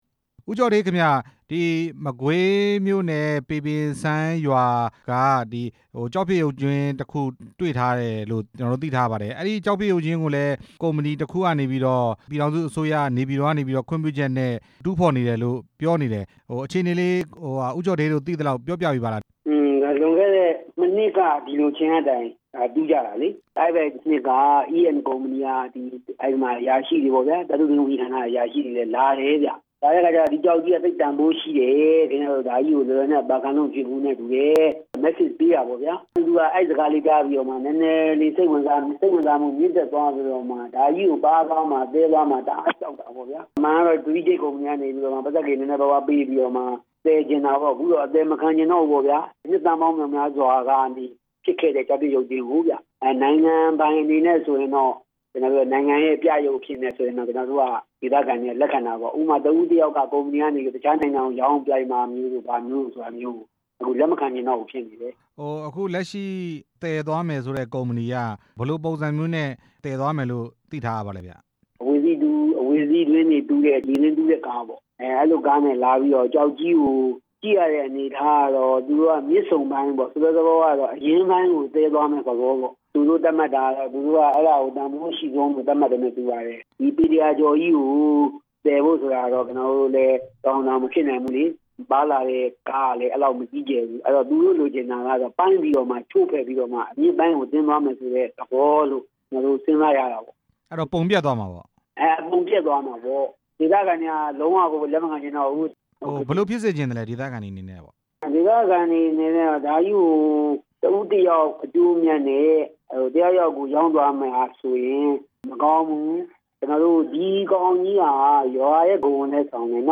ပေပင်စမ်းရွာက ကျောက်ဖြစ်ရုပ်ကြွင်းအကြောင်း မေးမြန်းချက်